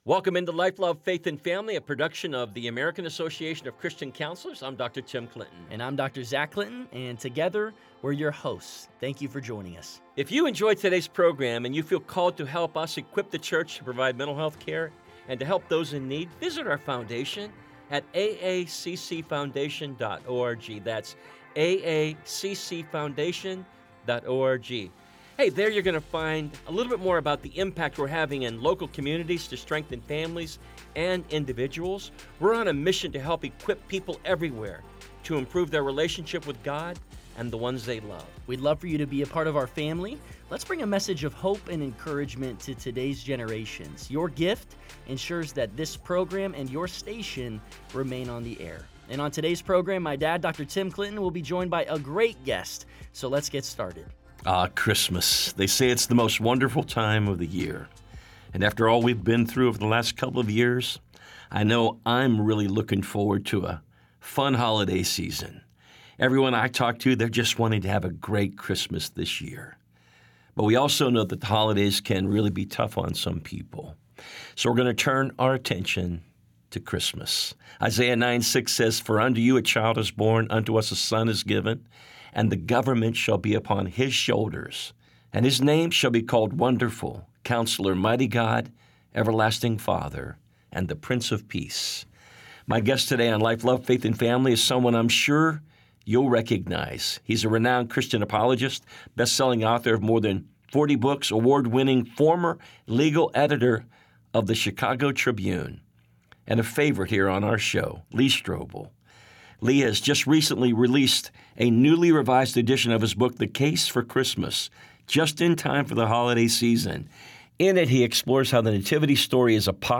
In part one of their conversation, Lee unpacks often-misunderstood details of the Christmas story and shares how his own journey from atheist journalist to believer began as he started investigating the historical evidence behind the Nativity accounts, the virgin birth, and key Old Testament prophecies.